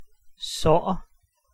Ääntäminen
Synonyymit wond wonde kwetsuur blessure letsel Ääntäminen Tuntematon aksentti: IPA: /vǝɾ.ʋɔn.dɪŋ/ Haettu sana löytyi näillä lähdekielillä: hollanti Käännös Ääninäyte 1. sår {n} 2. skade {c} Suku: f .